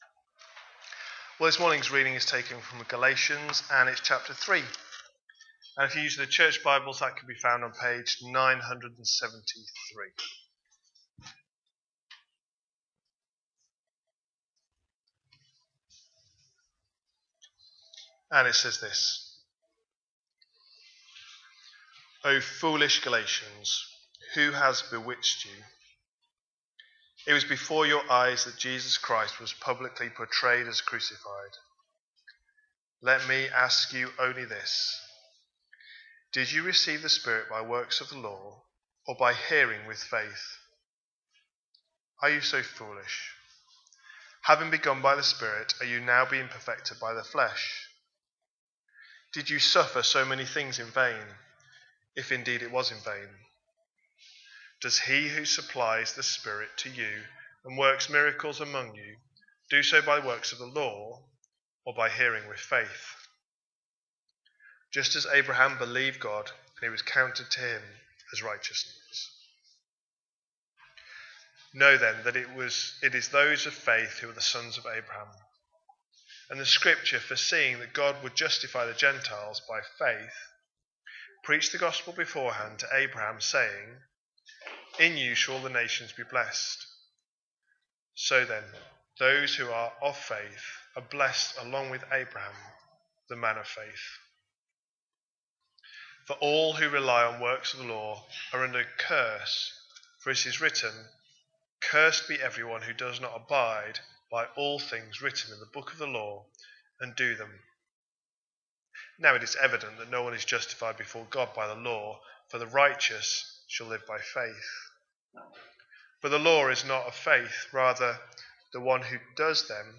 A sermon preached on 15th June, 2025, as part of our Galatians series.